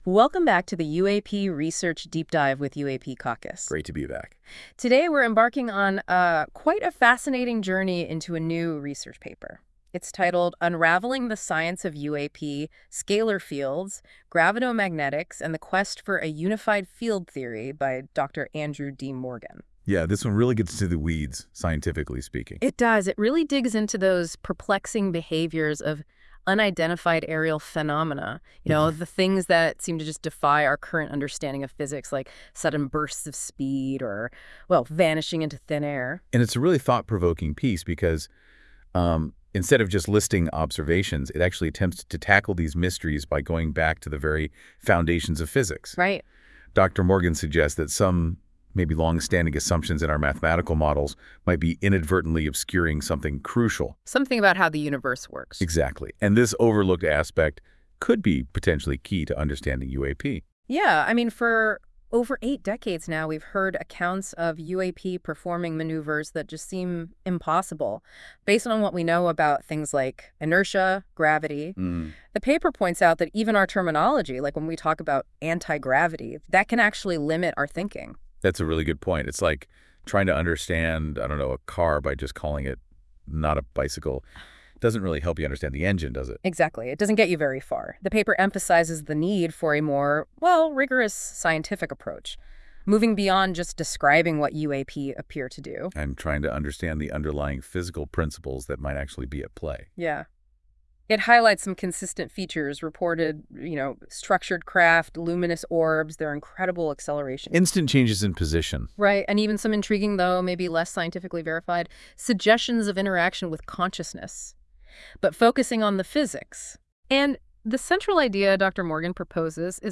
Powered by NotebookLM. This AI-generated audio may not fully capture the research's complexity.